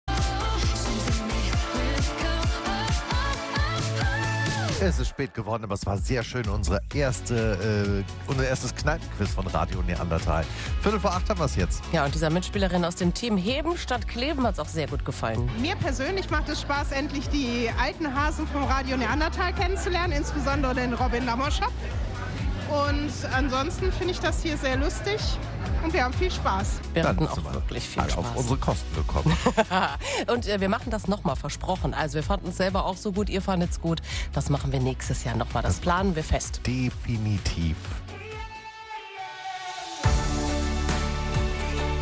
Das erste "Radio Neandertal Kneipenquiz" im Restaurant "Da Vinci" in Velbert. Dreizehn Teams hatten jede Menge Spass beim Rätseln, Quizzen und Musiktitel über Kreuz erkennen.